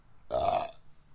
标签： 婴儿 好玩 哈哈 哈哈哈 大笑 微笑 声音 年轻的
声道立体声